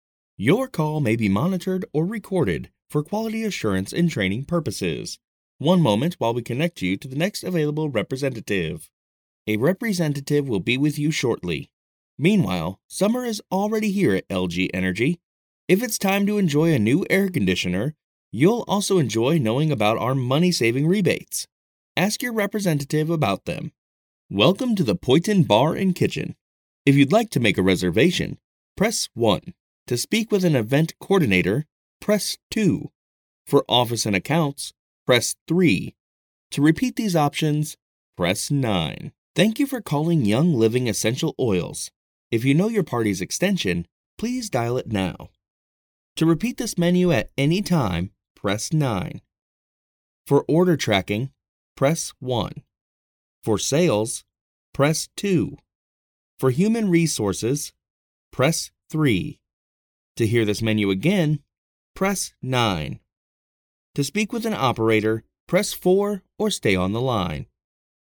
I have worked with some great clients such as WalMart, SNHU, Oil Changers INC, ETC. I bring professional Studio quality sound to you project.
Telephony
I specialize in strong, deep, guy next door, conversational, confident, friendly, and professional voice overs. i can provide a free sample if needed.
My experiences in vo include: • e learning • commercial • radio • tv • internet video • telephony • character • audio book • narration I use a rode nt microphone microsoft computer adobe audition daw.
telephony demo.mp3